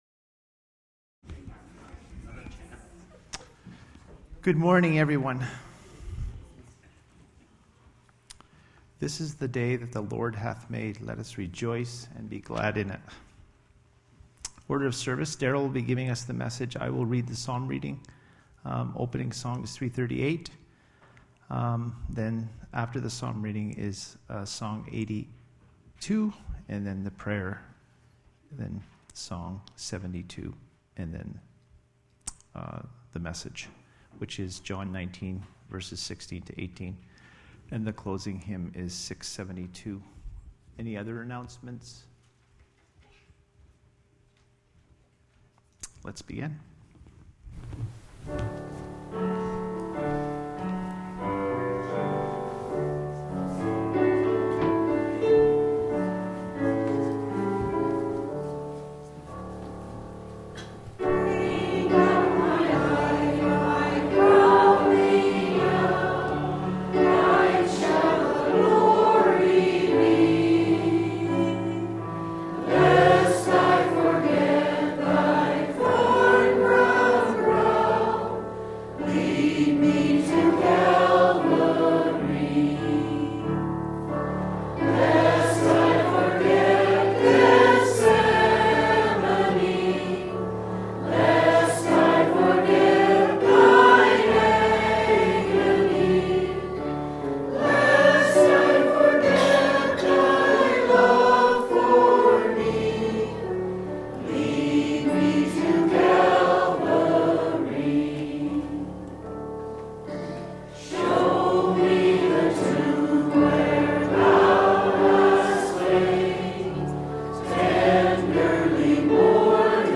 Service Type: Easter